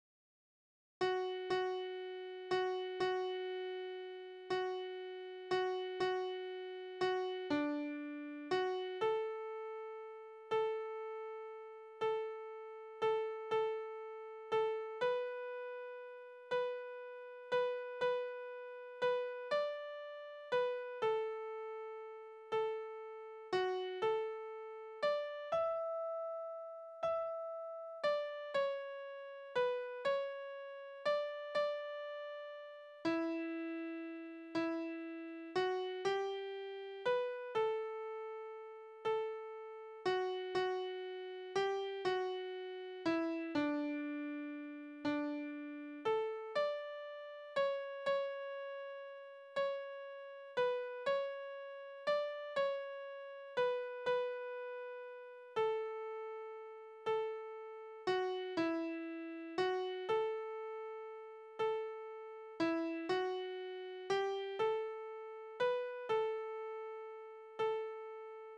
Liebeslieder:
Tonart: D-Dur
Taktart: 3/4
Tonumfang: große None
Besetzung: vokal
Anmerkung: die letzte Strophe besitzt eine eigene Melodie